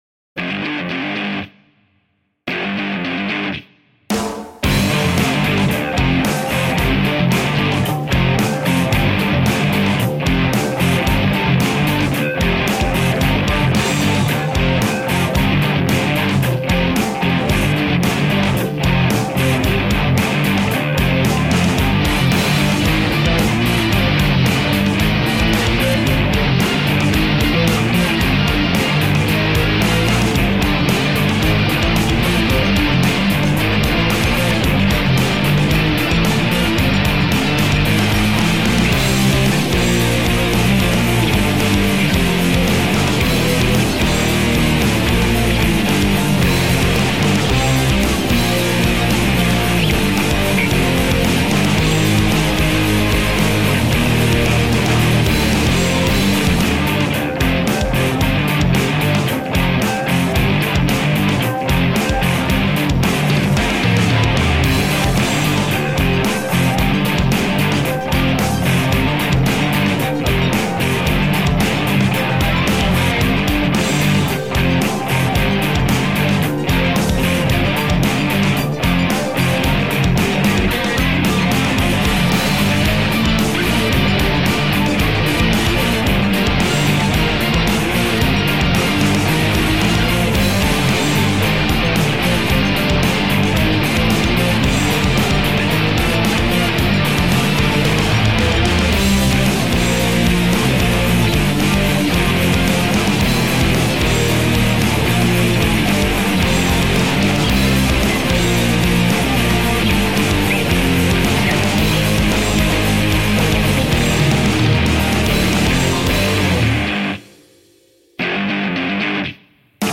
Pulse-pounding, booty-shaking rock.
Tagged as: Alt Rock, Hard Rock